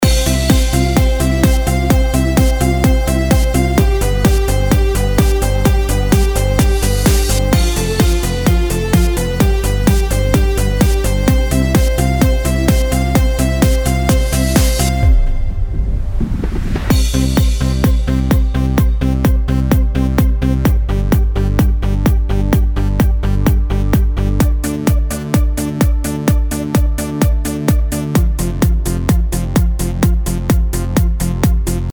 Popschlagersong im aktuellen Style.
Hier kannst du kurz ins Playback reinhören.